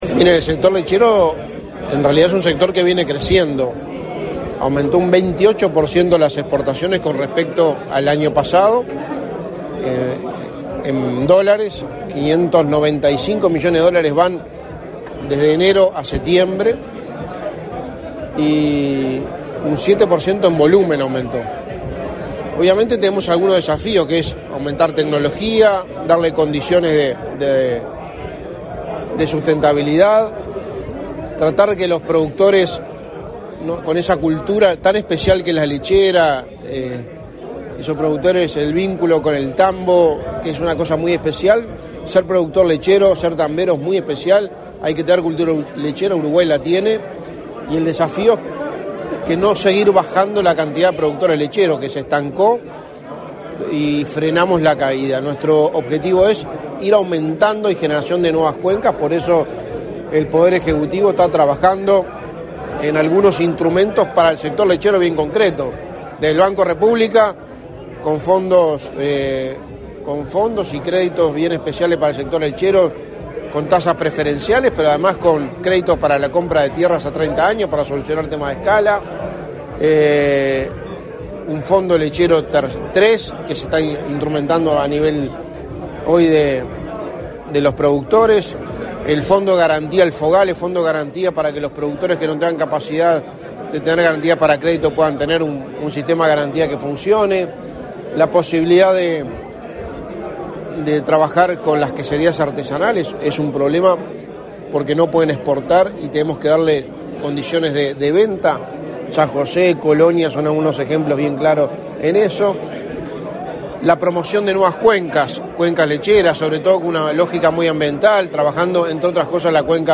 Declaraciones a la prensa del secretario de Presidencia, Álvaro Delgado
Declaraciones a la prensa del secretario de Presidencia, Álvaro Delgado 01/11/2022 Compartir Facebook Twitter Copiar enlace WhatsApp LinkedIn Tras participar en el acto de lanzamiento oficial de Mercoláctea en Uruguay, este 1 de noviembre, el secretario de la Presidencia realizó declaraciones a la prensa.
Delgado prensa.mp3